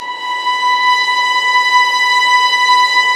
Index of /90_sSampleCDs/Roland LCDP13 String Sections/STR_Violins II/STR_Vls6 p wh%